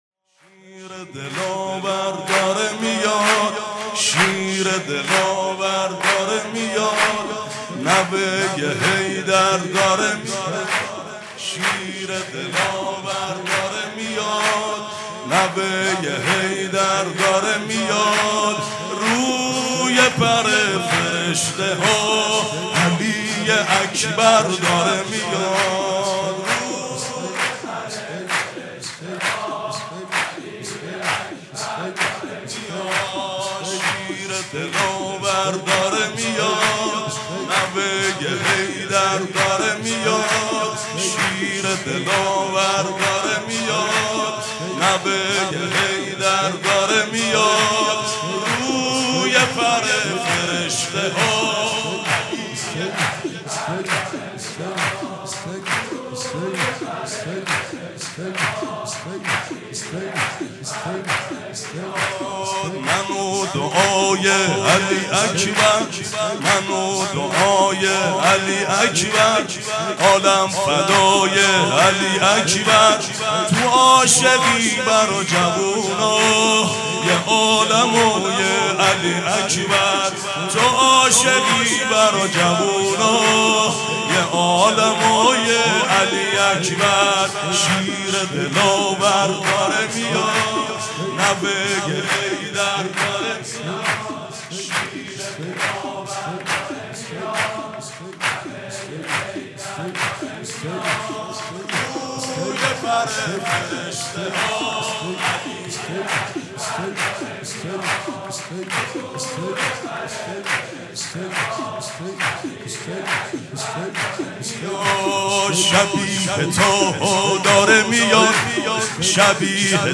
شب میلاد حضرت علی اکبر (ع)/ هیأت رایة العباس (ع)
شور و مناجات